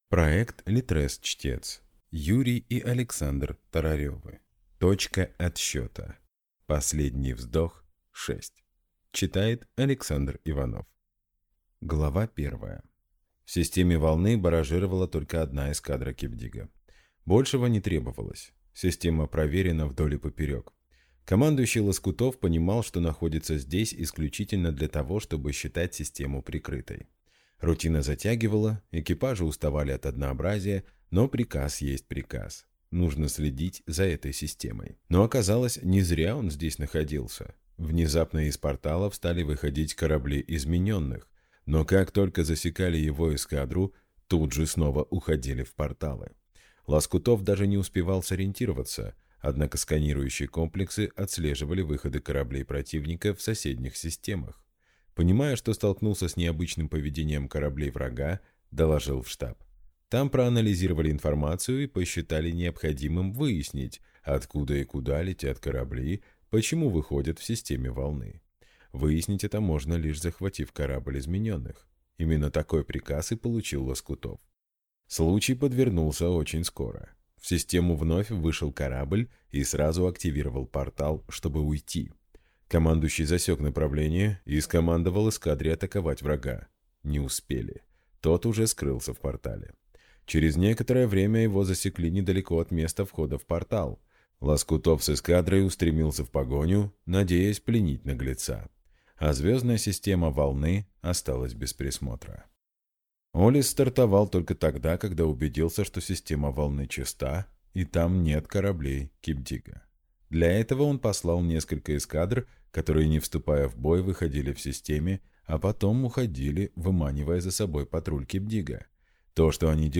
Аудиокнига Точка отсчета | Библиотека аудиокниг